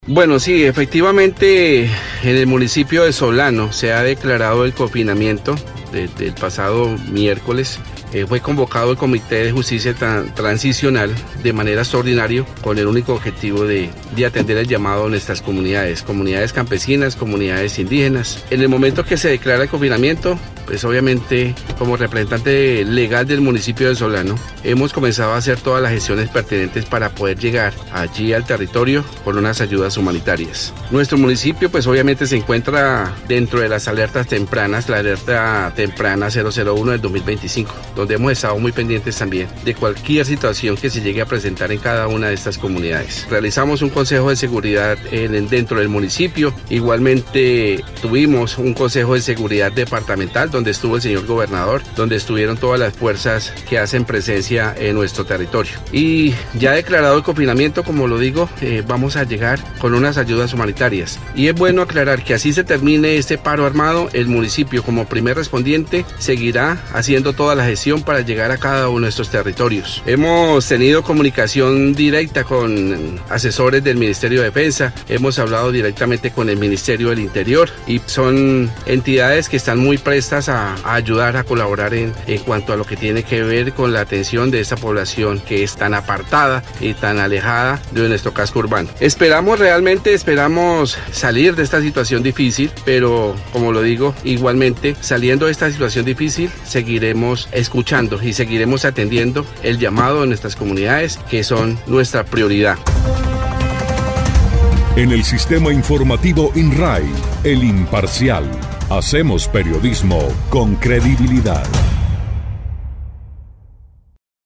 Luis Hernando Gonzales Barreto, alcalde del municipio de Solano, explicó que, de inmediato se trabaja en llevar ayudas humanitarias a las poblaciones más apartadas del casco urbano, esto con el apoyo de ministerios como defensa e interior.
01_ALCALDE_LUIS_GONZALES_CONFINAMIENTO.mp3